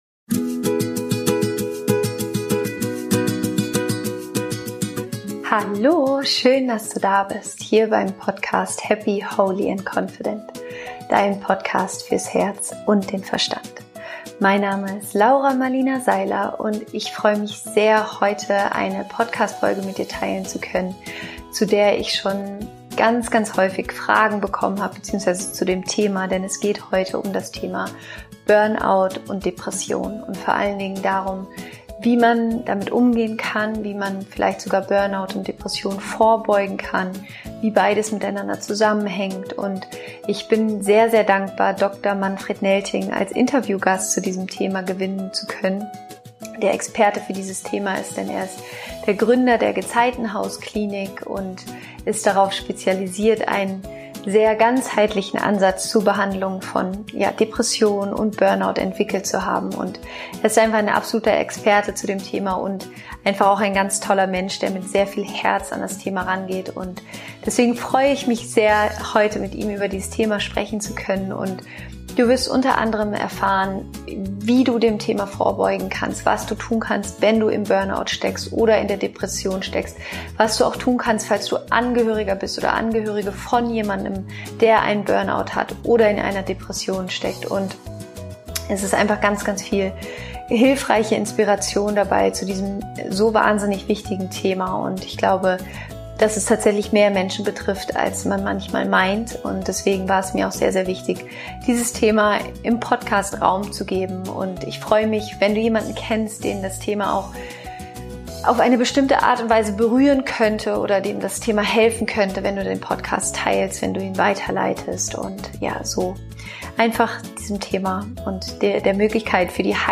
Der Weg raus aus dem Burnout und Depression - Interview